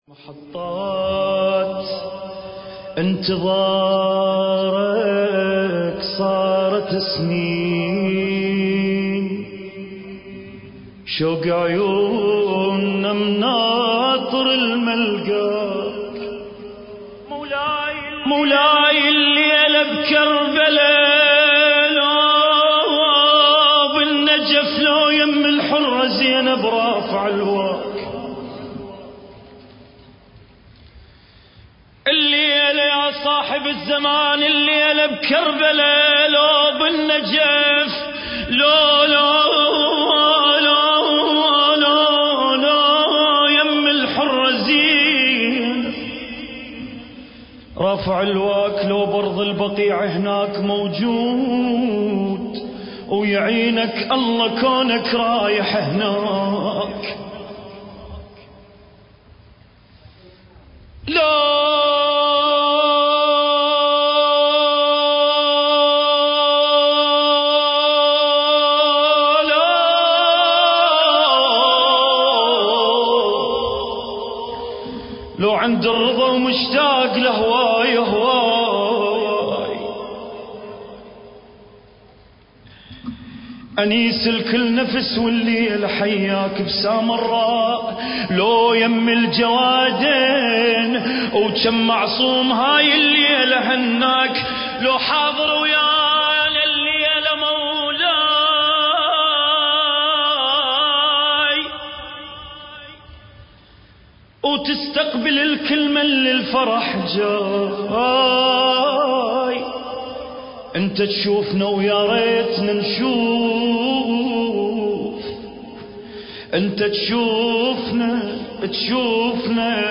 المكان: حسينية عاشور- الكويت